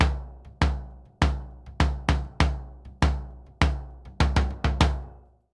Bombo_Samba 100_2.wav